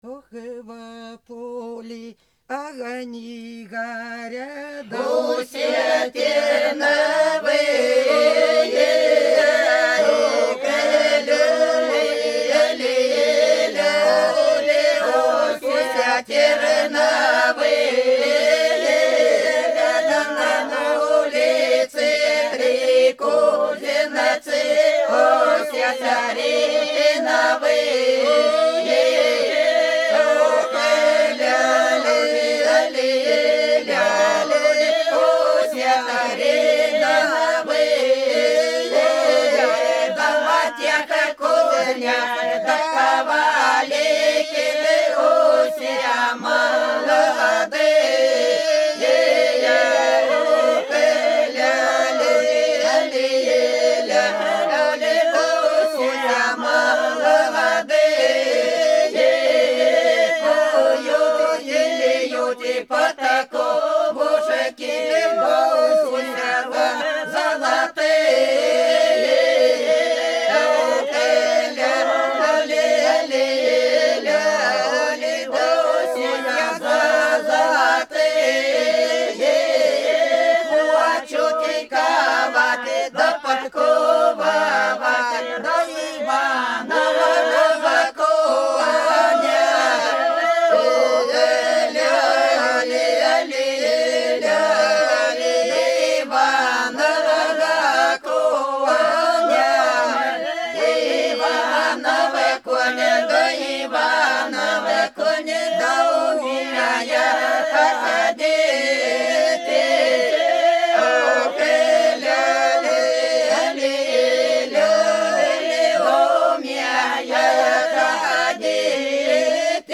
Пролетели все наши года Во поле огни горят все терновые – свадебная (Фольклорный ансамбль села Подсереднее Белгородской области)